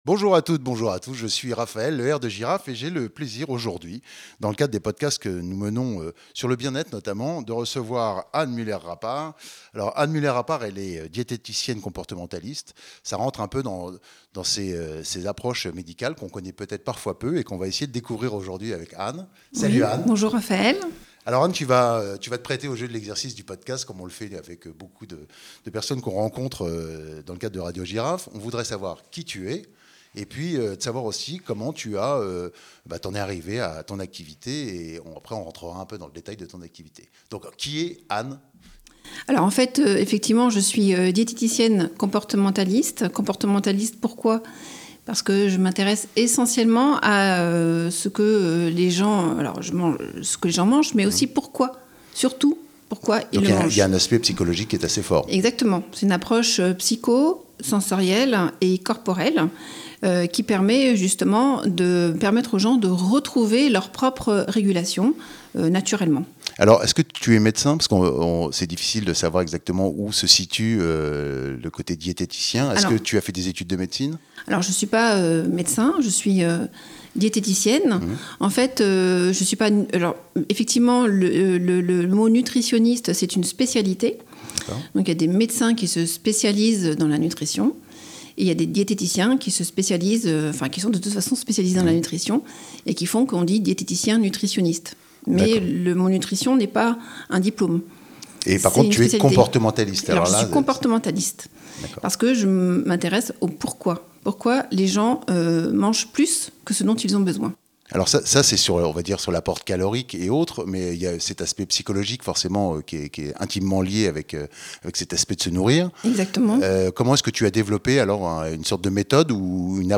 Diététicienne et comportementaliste